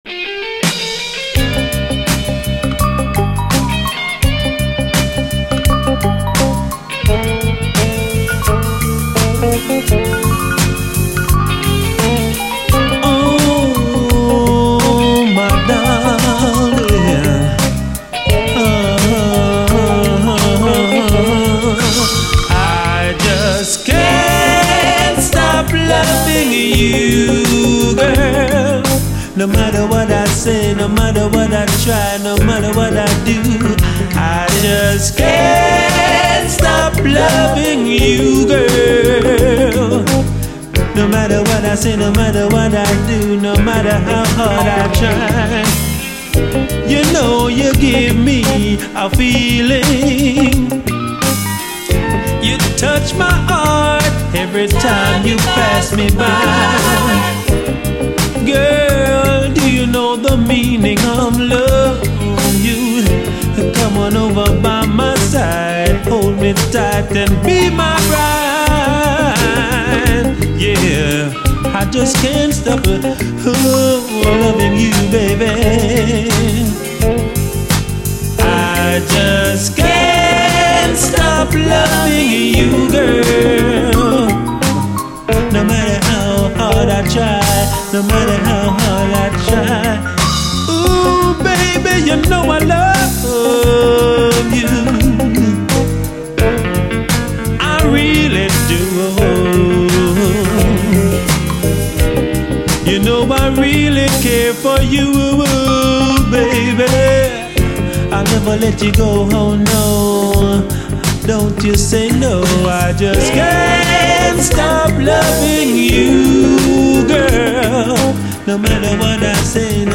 REGGAE
ギラついたギターが他にはない魅力！
ギラついたイントロのギター使いがなかなか他にはない突出した魅力！しっとりとアーバン、アダルトな一曲！